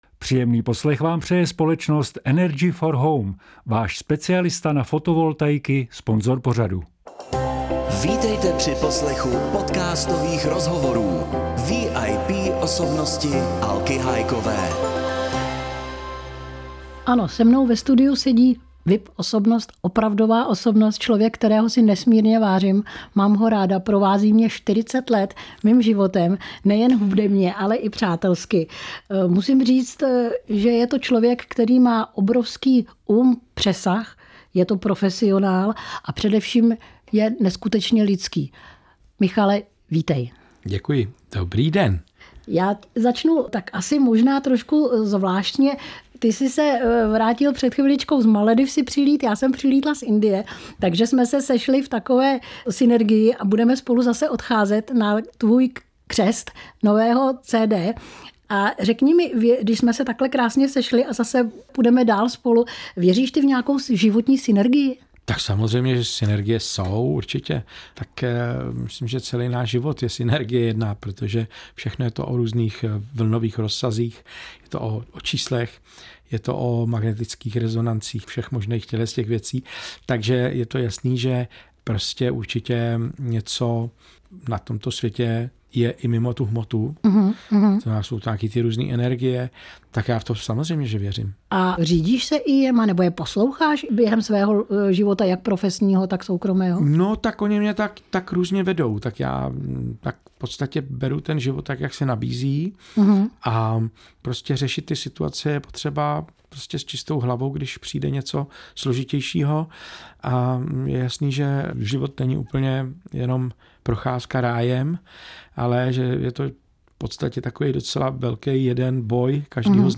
Hitmaker a zpěvák se v rozhovoru zamýšlí, proč si dnes mladí lidé mezi sebou mladí lidé nepovídají.